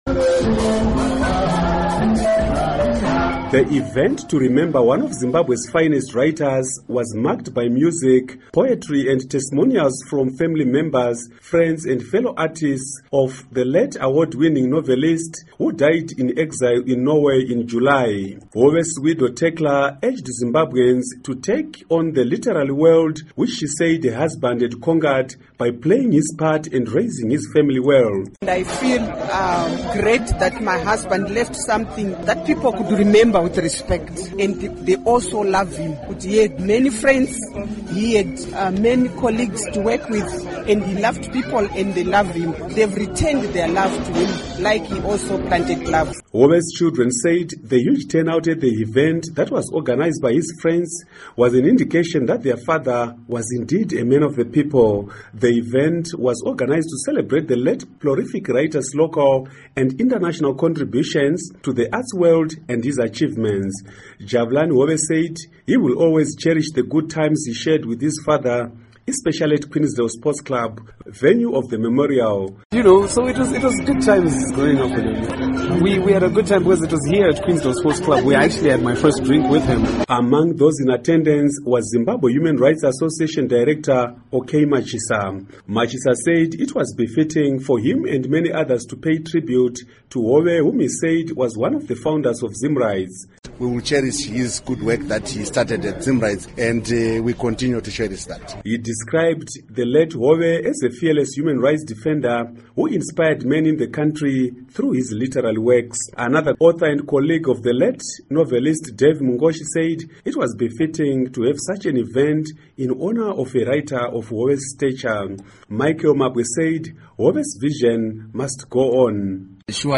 Report on Chenjerai Hove's Memorial